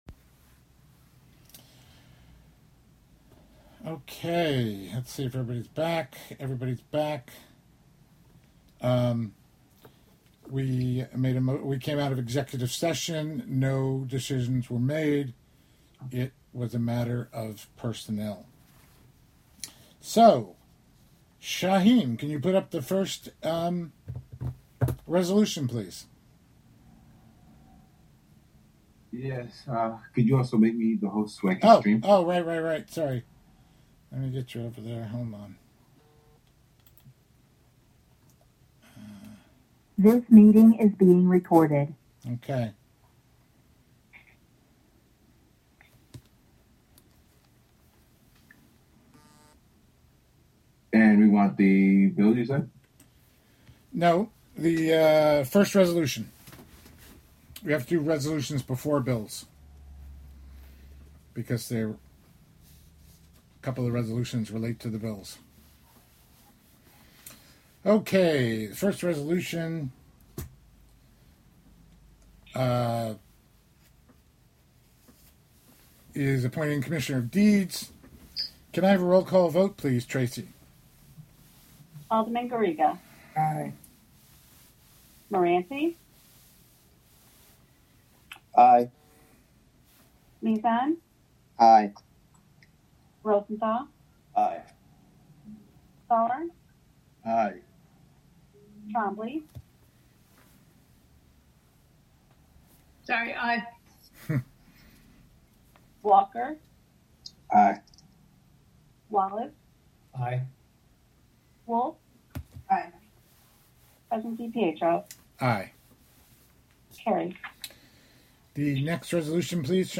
Live from the City of Hudson: Hudson common council formal meeting (Audio)